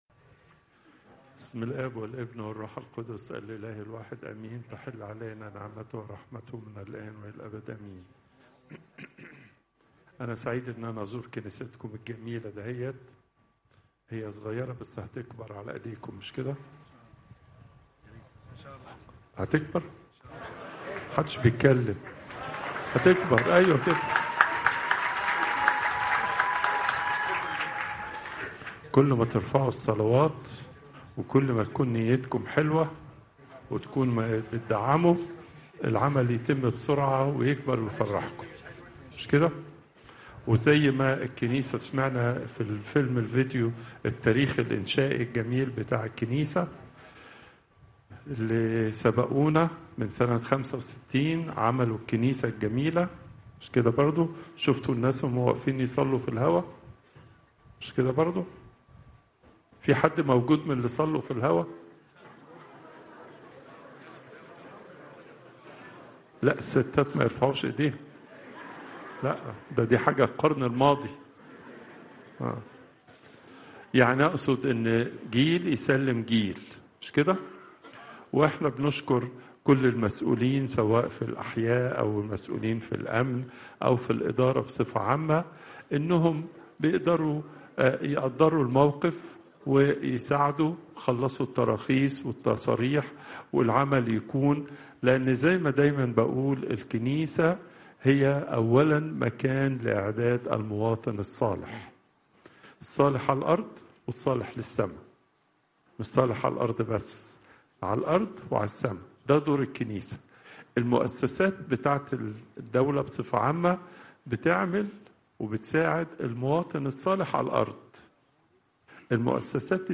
Popup Player Download Audio Pope Twadros II Wednesday, 17 December 2025 37:34 Pope Tawdroes II Weekly Lecture Hits: 339